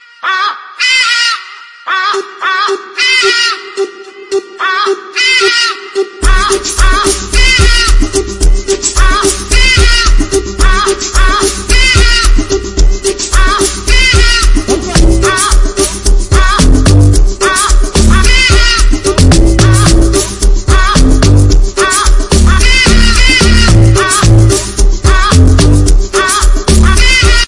hadeda.mp3